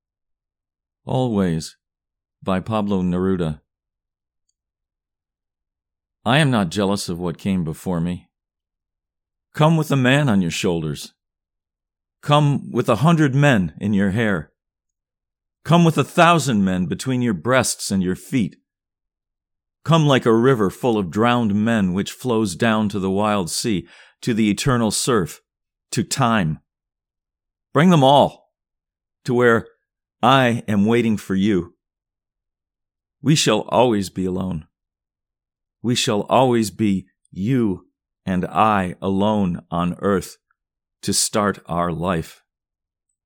Always © by Pablo Neruda (Recitation)